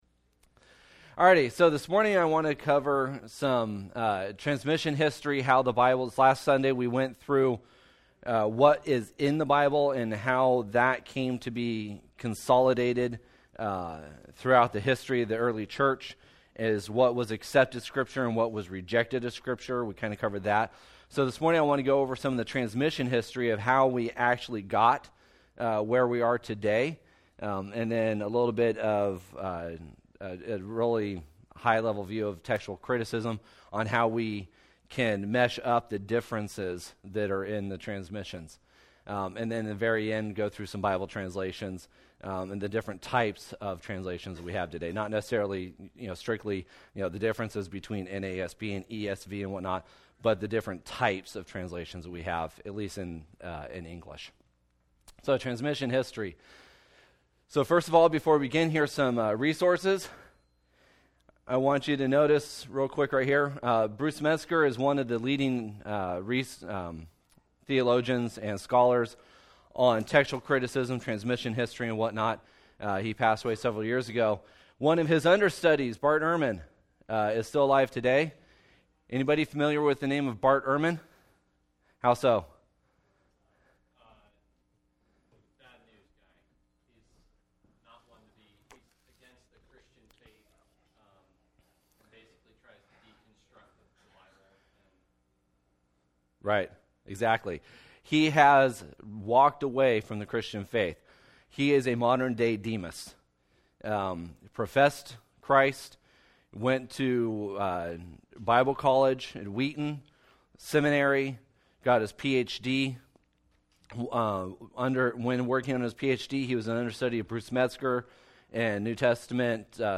Sunday School: Transmission history, textual criticism, and Bible translations